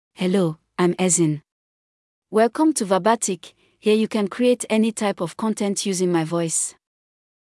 Ezinne — Female English (Nigeria) AI Voice | TTS, Voice Cloning & Video | Verbatik AI
FemaleEnglish (Nigeria)
Voice sample
Female
Ezinne delivers clear pronunciation with authentic Nigeria English intonation, making your content sound professionally produced.